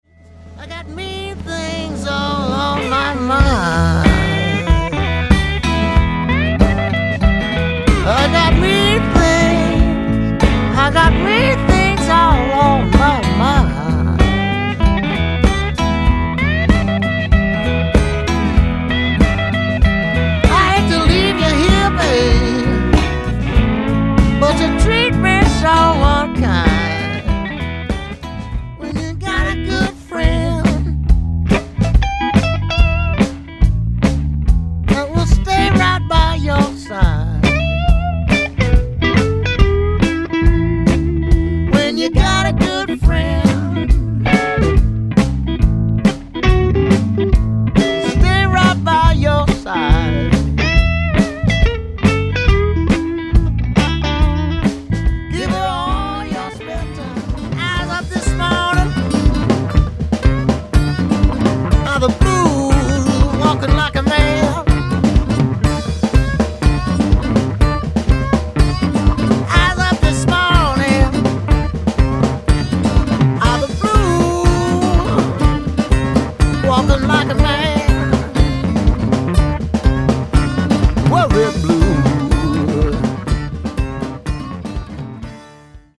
Genre/Style: Blues